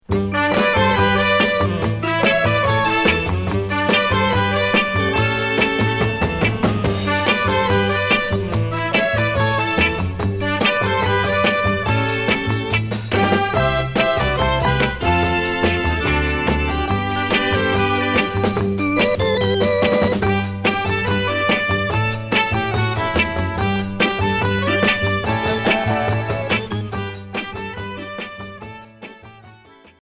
Jerk EP qui tourne en 33t retour à l'accueil